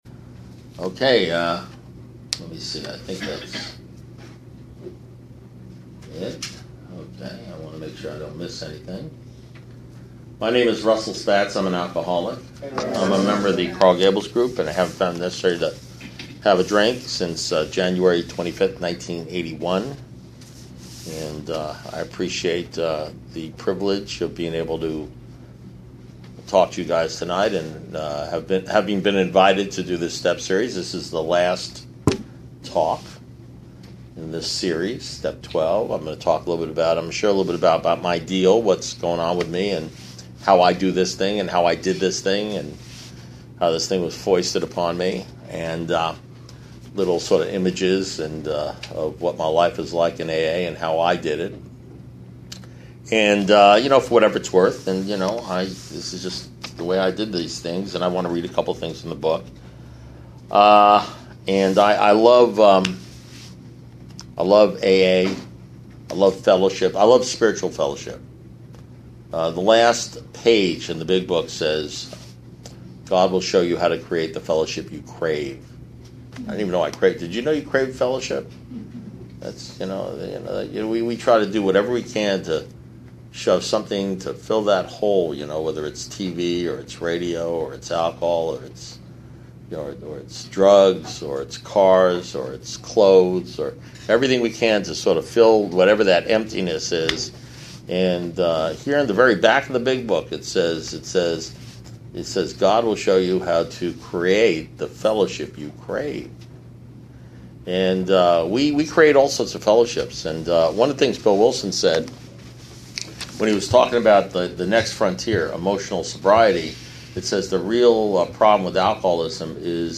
Alcoholics Anonymous Speaker Recordings